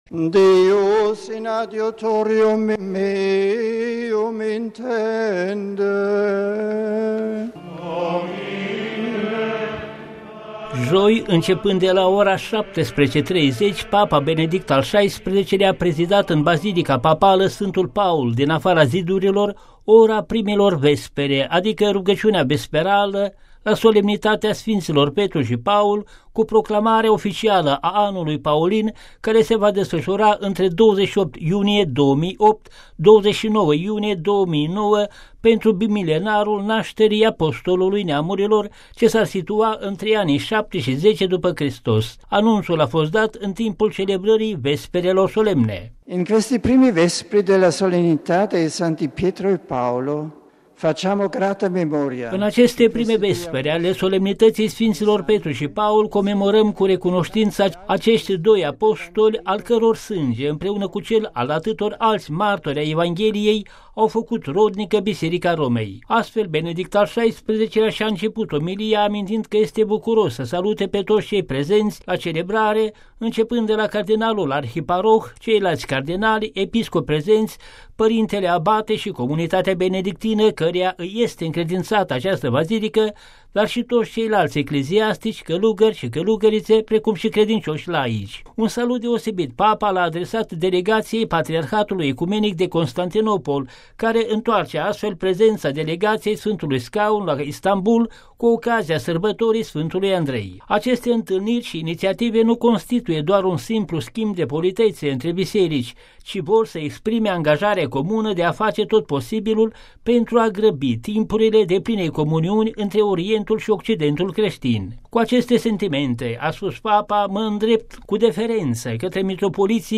Celebrând joi primele Vespere în bazilica papală Sfântul Paul din afara Zidurilor, Papa lansează Anul Paulin. În întregime predica lui Benedict al XVI-lea
Anunţul a fost dat în timpul celebrării Vesperelor solemne.